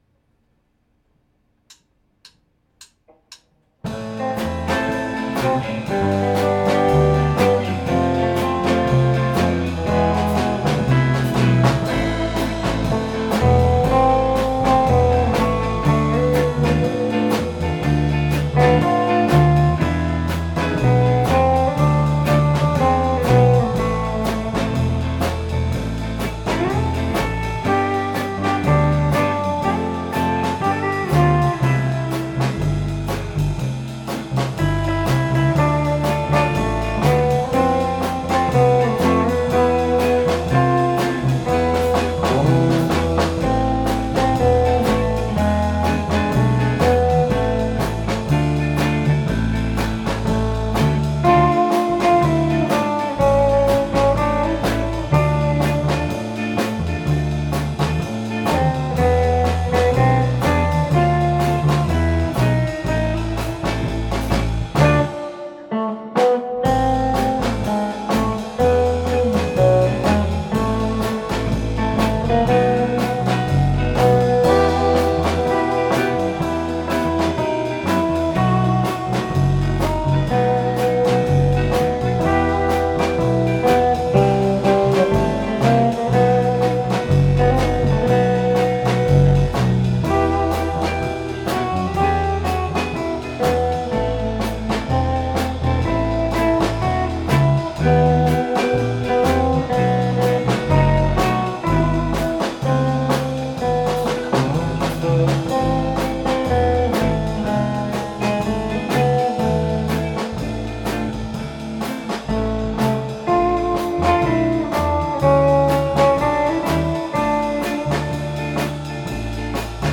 リードギター
ベースギター
ドラム
２ｎｄギター